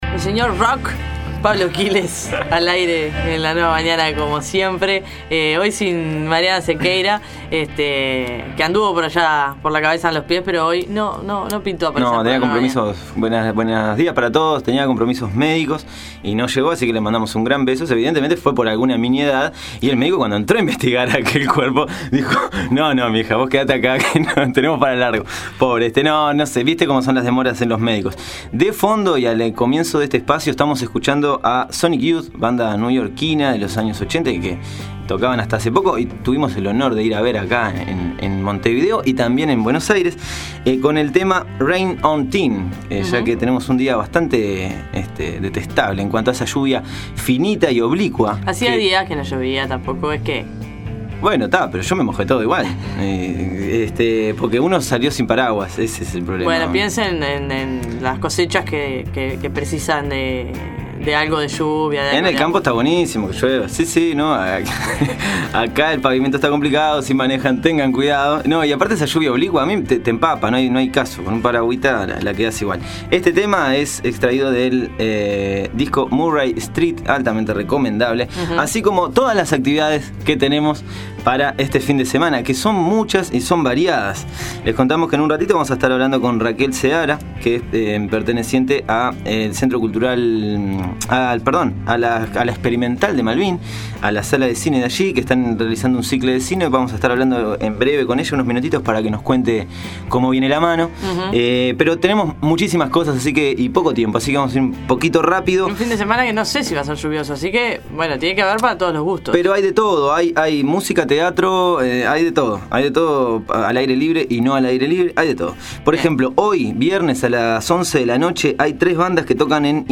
Audio: Domingos de cine en La Experimental de Malvin. Entrevista